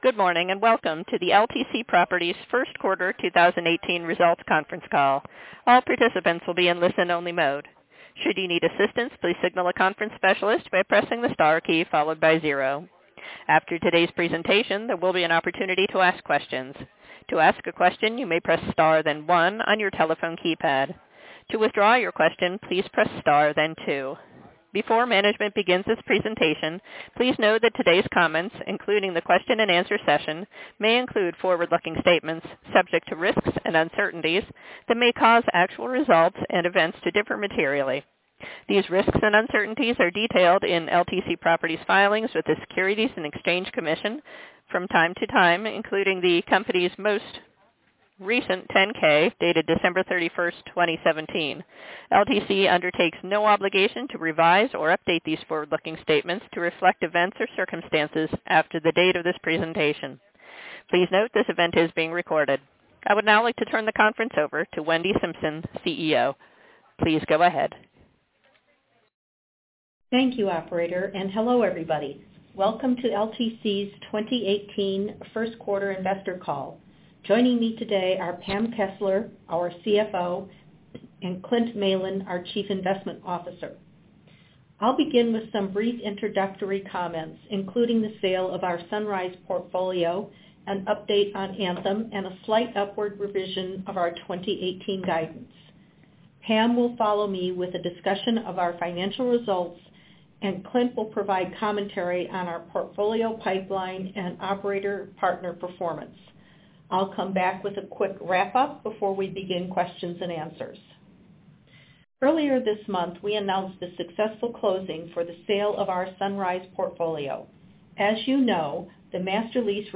Audio-Replay-of-LTC-Properties-Inc-Q1-2018-Earnings-Call.mp3